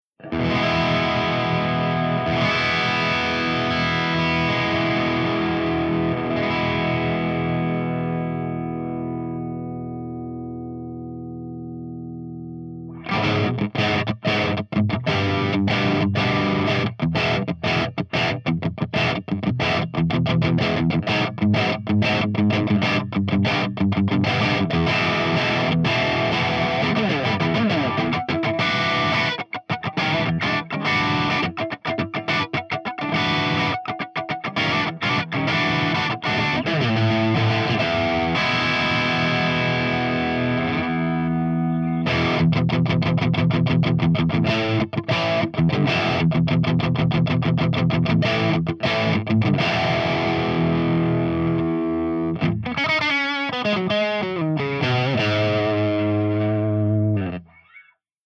156_EVH5150_CH2CRUNCH_V30_SC